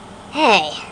Hey (cartoon) Sound Effect
Download a high-quality hey (cartoon) sound effect.
hey-cartoon.mp3